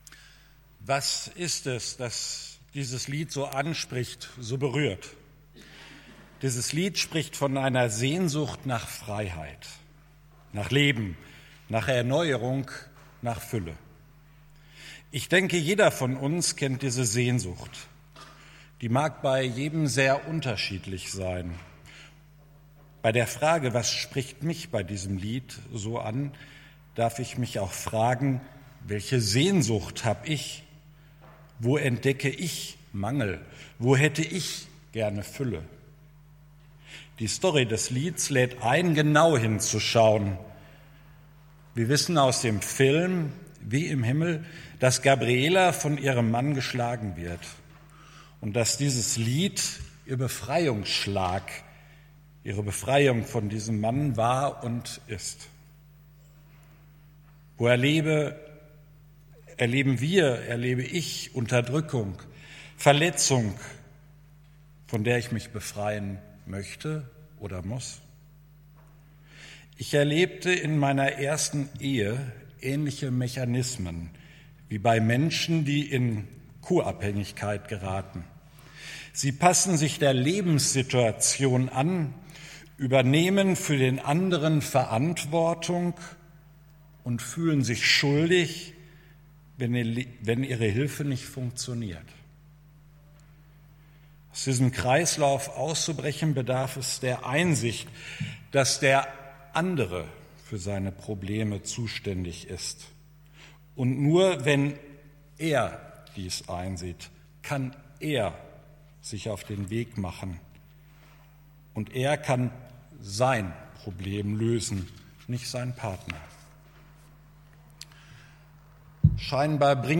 Juli 14, 2019 | Predigten | 0 Kommentare
Gottesdienst vom 14.07.2019